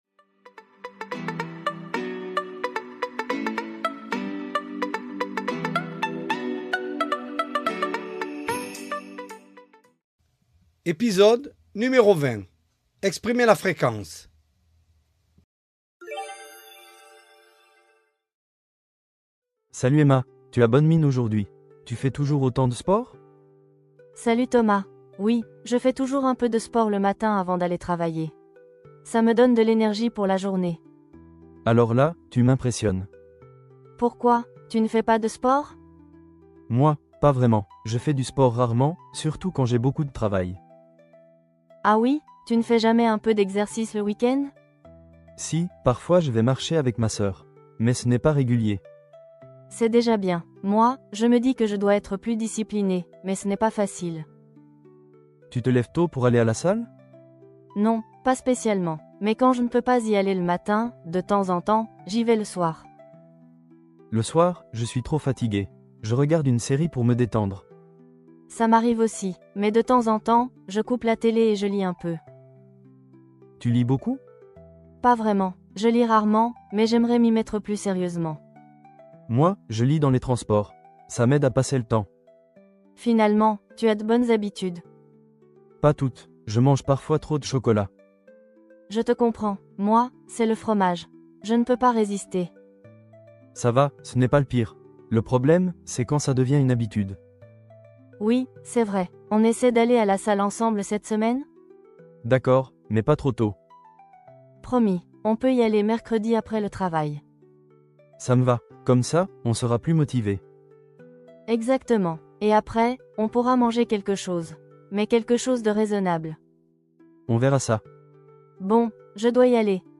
Voici un dialogue pour les élèves de niveau A1 sur une conversation entre deux amis pour apprendre a exprimer la fréquence.